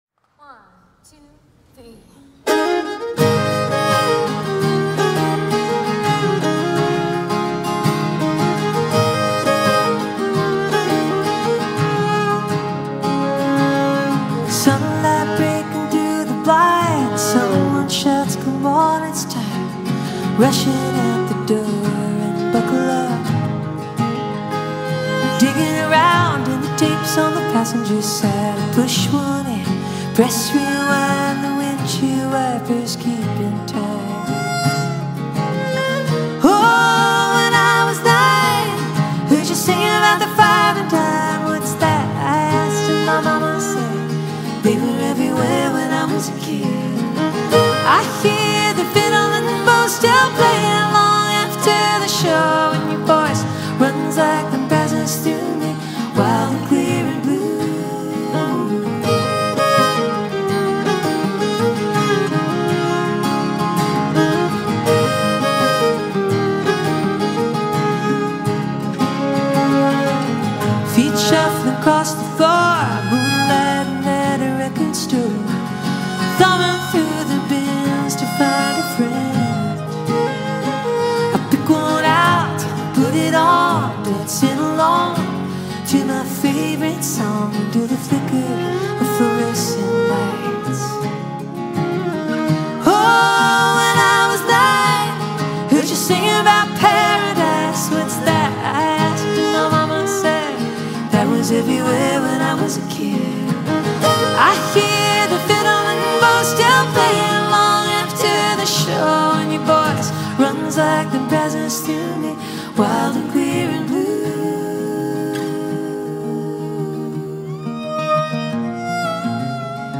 It delivers a sound that stays consistent throughout.